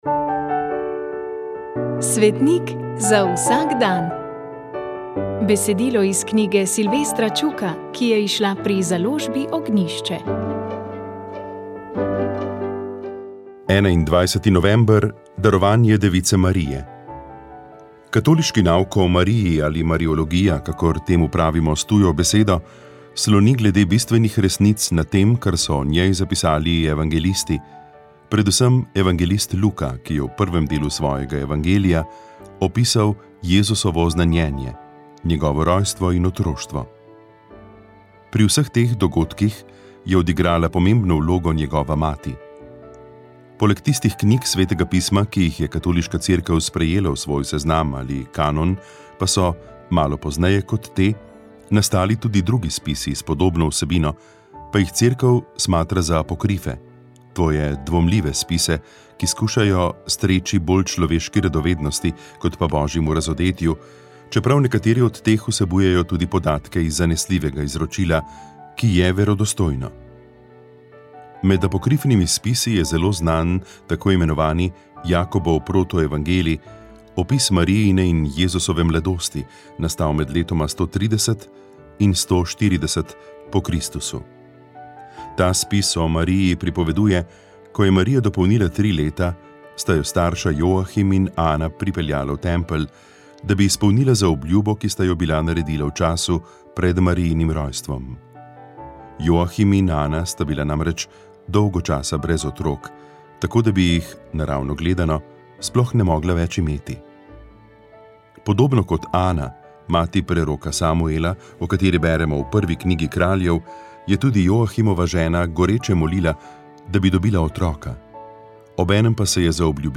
Toktat ste lahko prisluhnili pogovoru